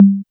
CONGA808-1.wav